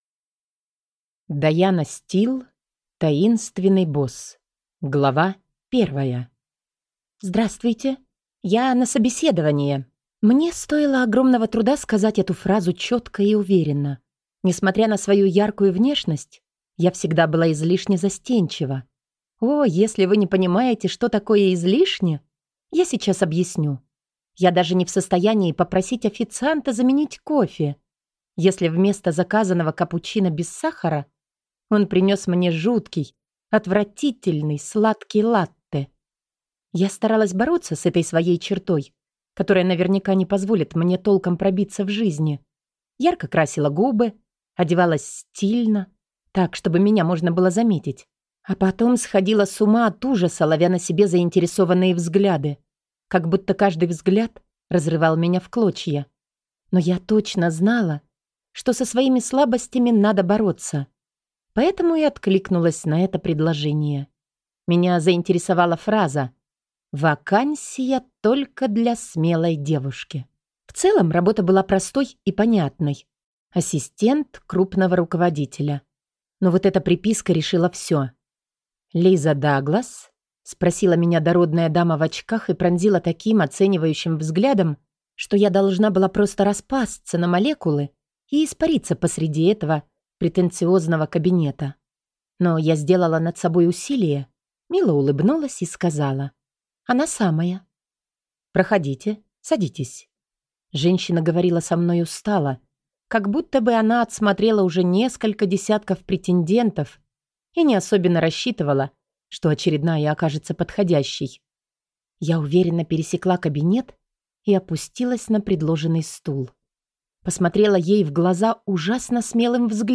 Аудиокнига Таинственный босс | Библиотека аудиокниг